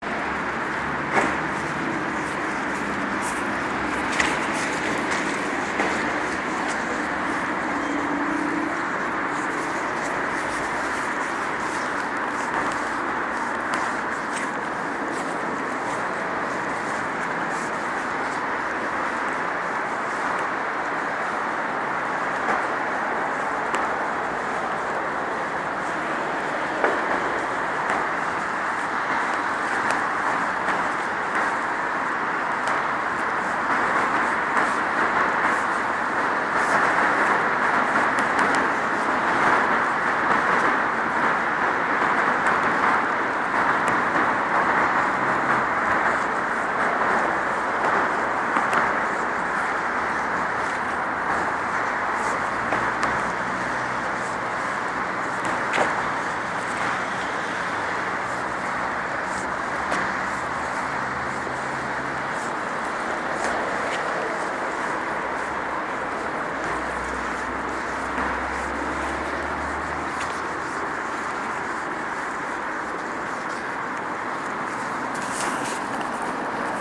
Field recording through underpass, below Mancunian Way, and through second underpass 09.09 25 January 2013